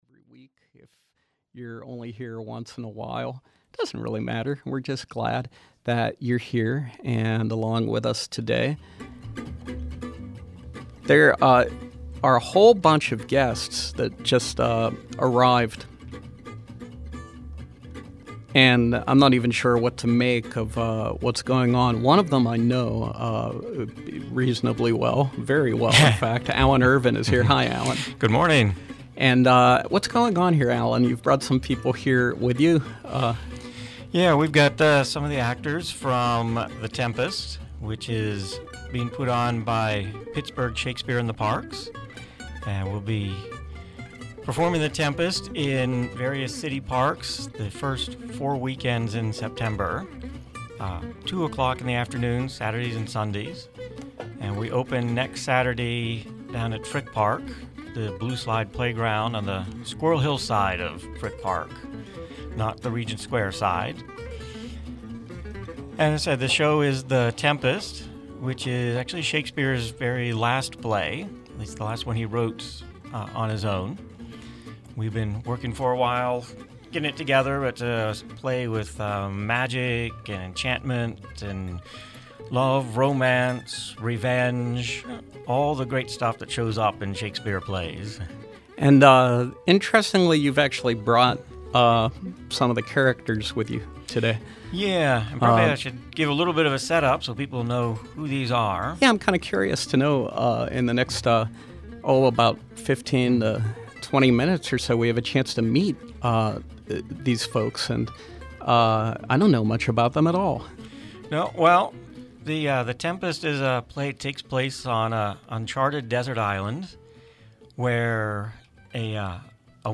Meet some of the cast and hear a reading from the play, live on SLB.